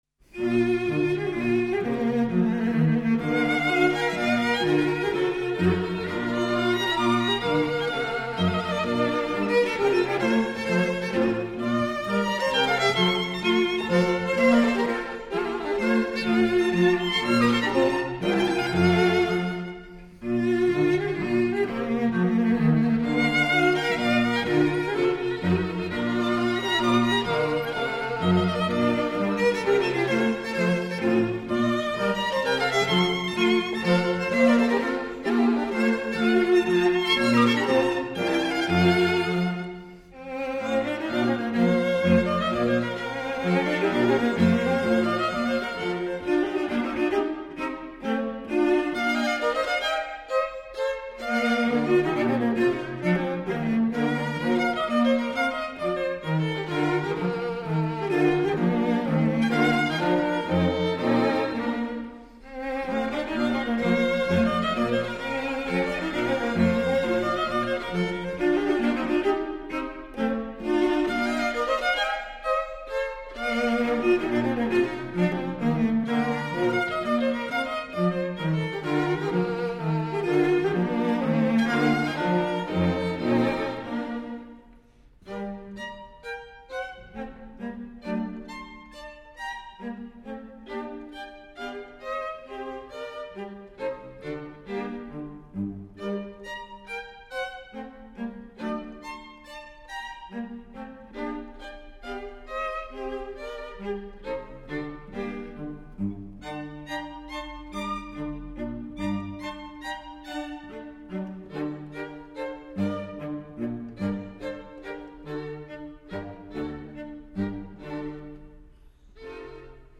String Quartet in B flat major
Menuetto